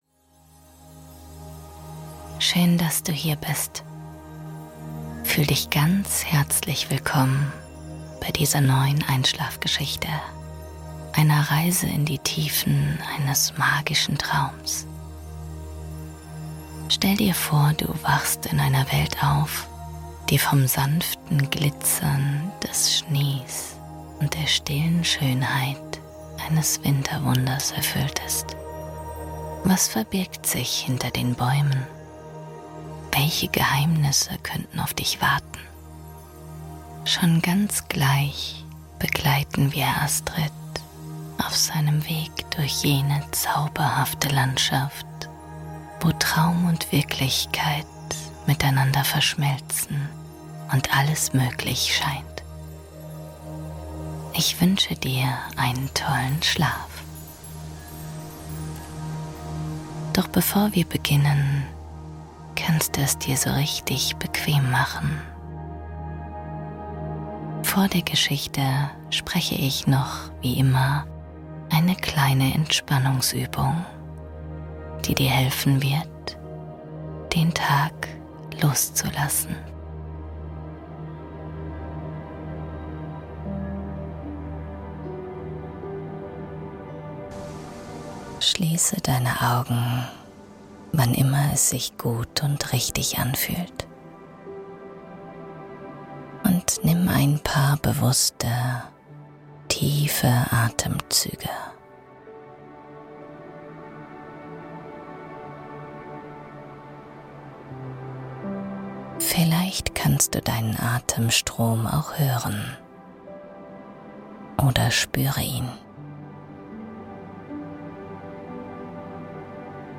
Meeresdorf-Meditation - Wellen und Regen singen dich in den Schlaf